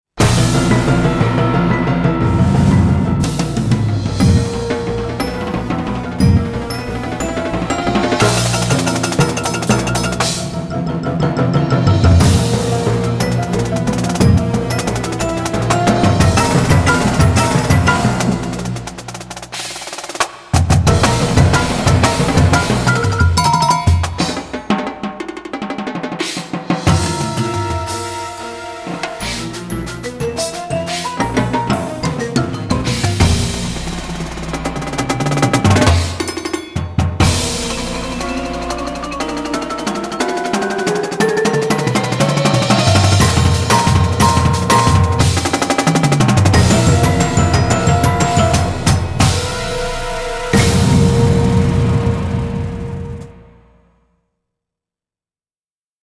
Advanced Marching Feature